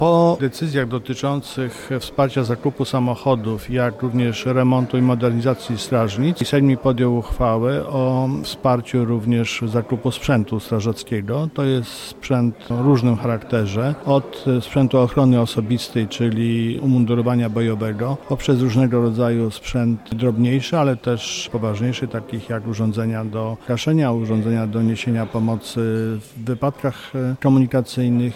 Marszałek województwa mazowieckiego, Adam Struzik mówi, że za otrzymane pieniądze OSP będzie mogło korzystać z profesjonalnego sprzętu strażackiego.